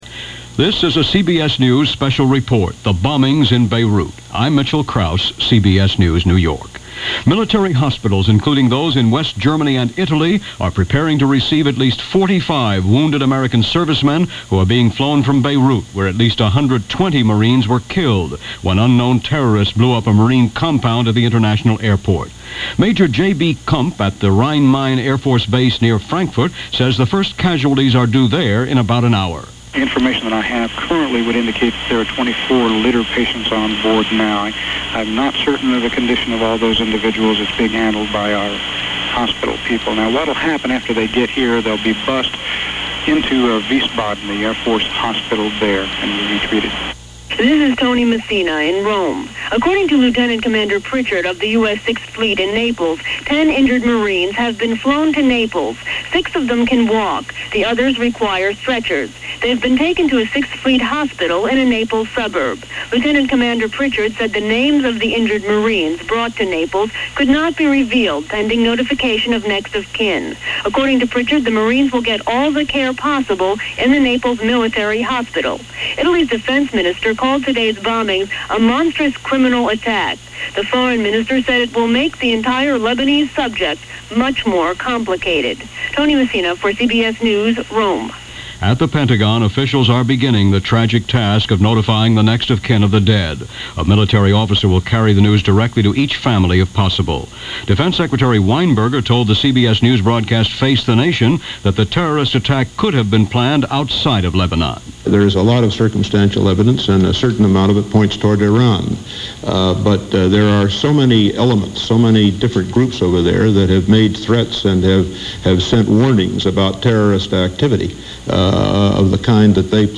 Beirut Barracks Bombing: Not With Open Arms - October 23, 1983 - news reports and reactions from CBS Radio News.
CBS-Radio-Beirut-Bombing.mp3